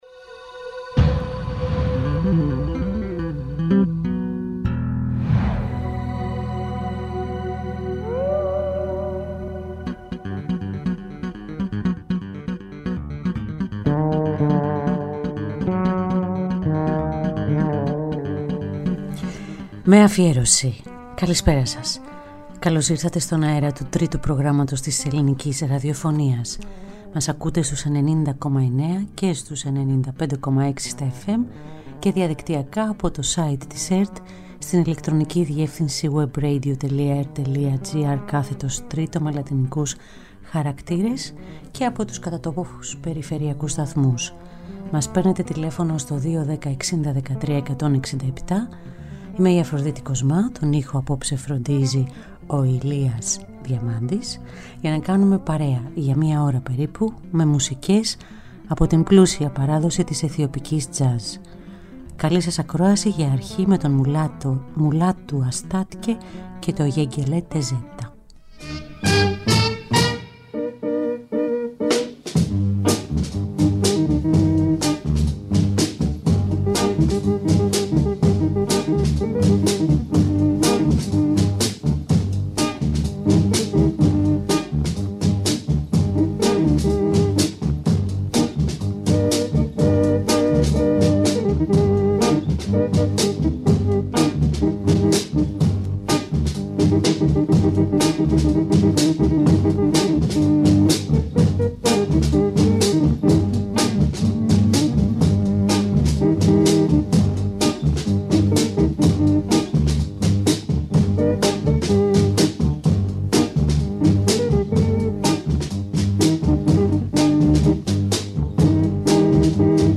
Ethiopian Grooves
Κάθε Πέμπτη, ζωντανά από το στούντιο